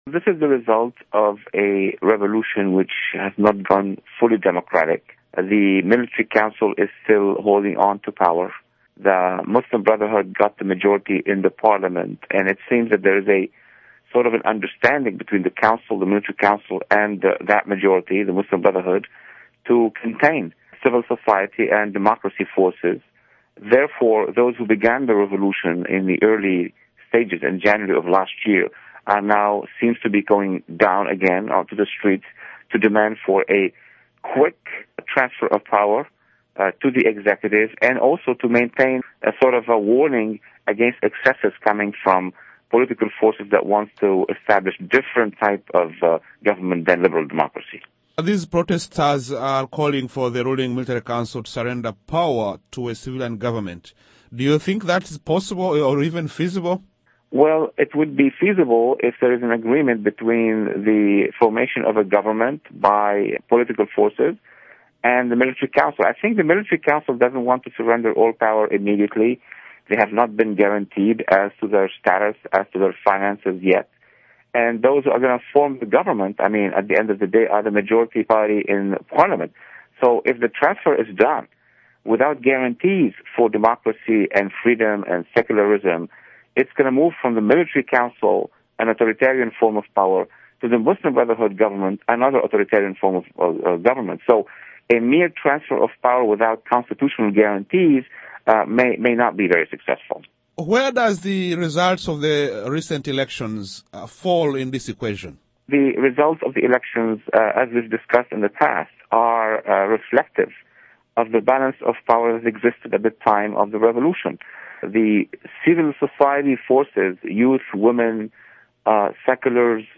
interview with Dr. Walid Phares